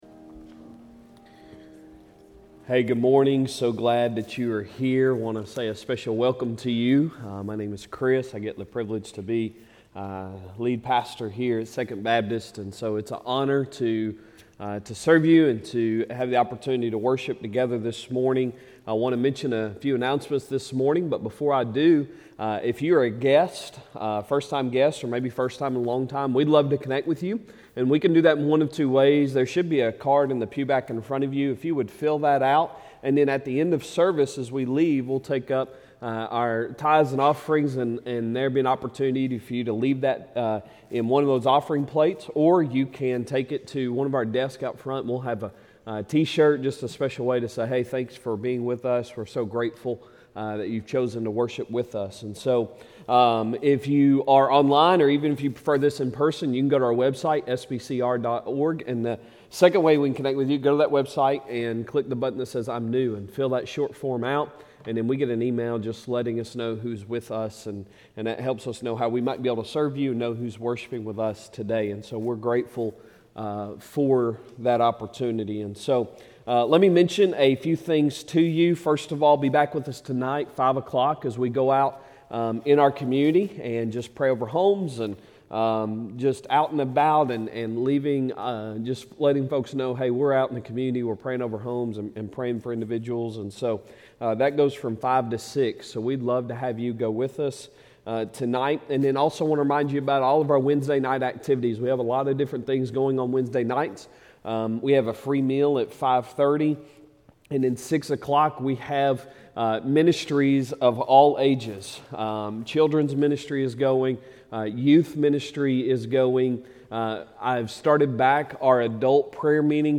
Sunday Sermon September 11, 2022